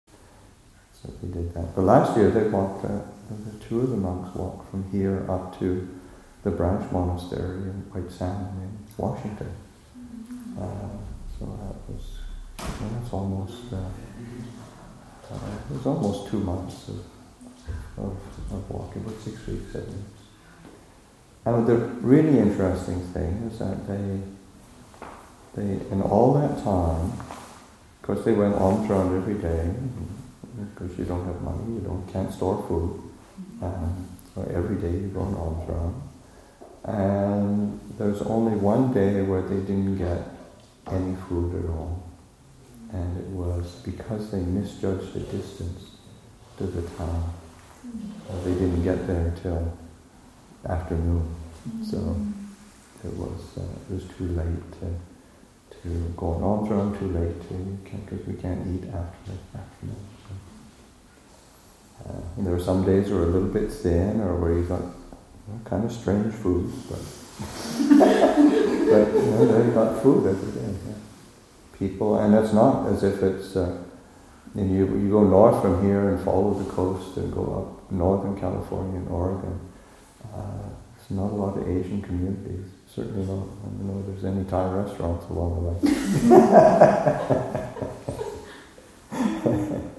Questions and Answers with Dharma Realm Buddhist University – Nov. 18, 2014